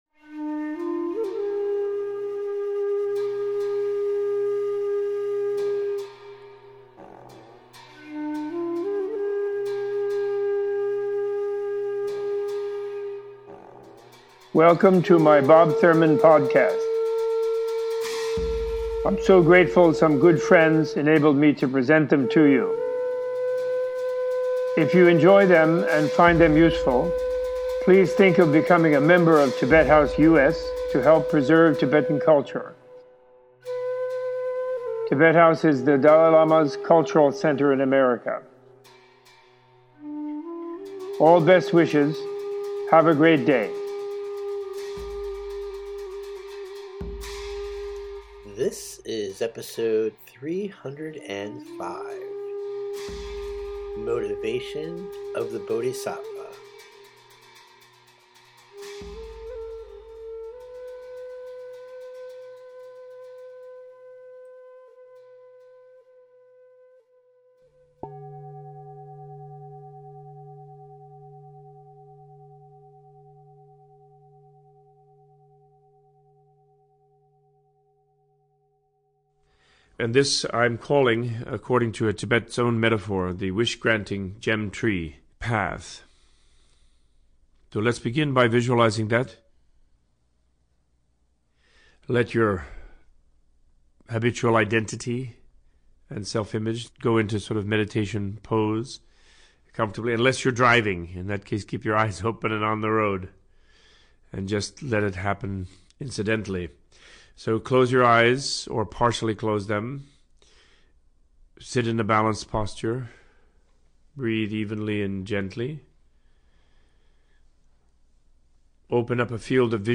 Opening with a guided “Jewel Tree” meditation, Robert Thurman gives an introduction to the Buddhist concepts of bodhisattvas, bodhichitta--the spirit of becoming perfectly awakened--and to the role of motivation in Buddhism and in everyday interactions. Using the classical imagery of the covered, leaky and poisoned vessels, Thurman gives a teaching on the qualities needed to be an open-minded, determined and well-intended student of Buddha Dharma and of life.